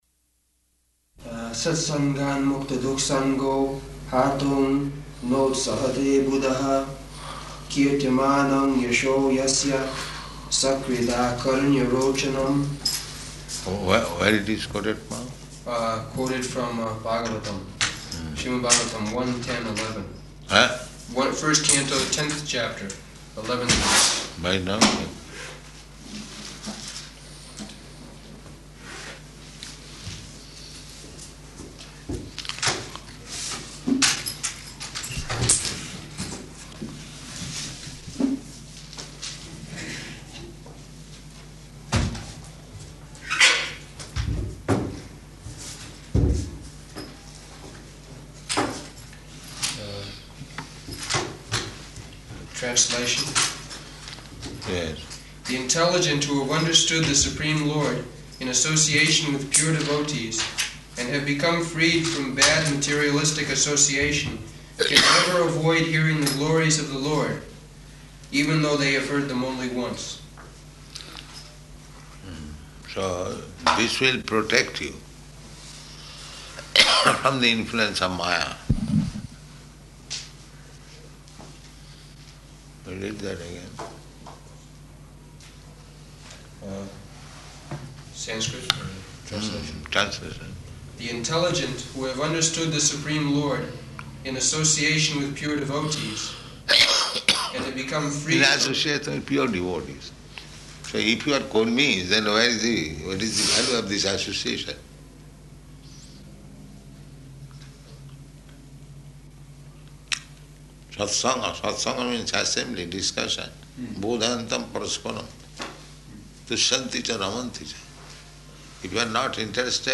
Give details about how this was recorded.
Location: Delhi